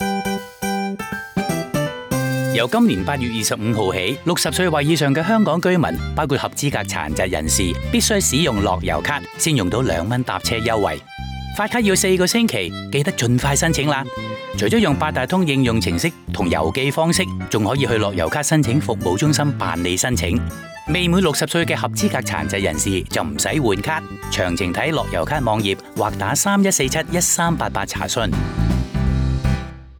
電台廣播